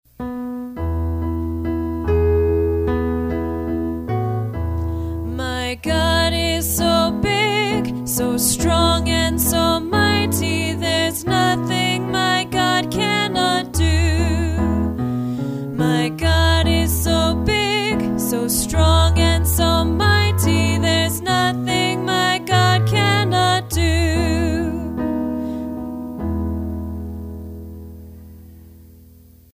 My-God-Is-So-Big-vocal-demo.mp3